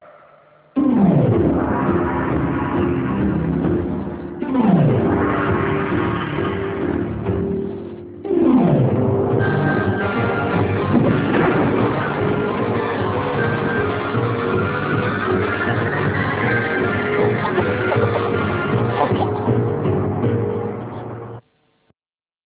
A series of blackouts in anecdotal form without narration on life in the contemporary world.